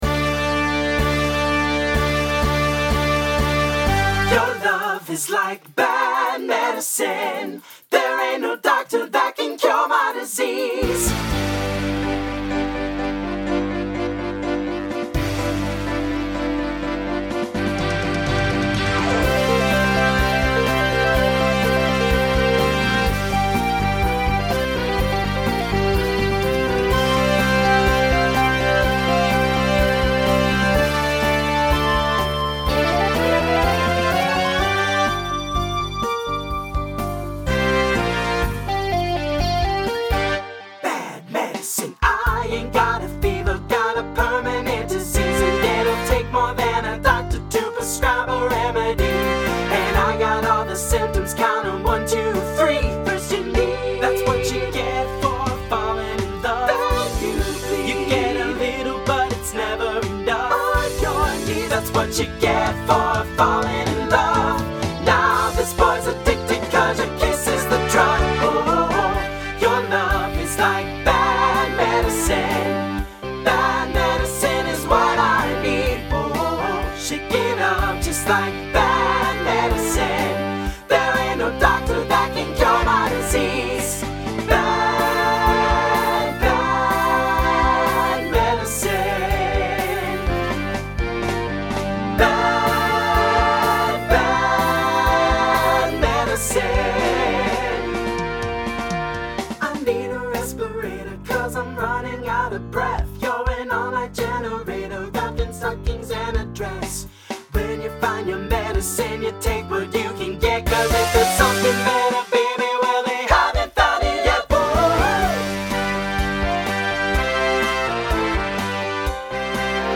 New SSA voicing for 2024.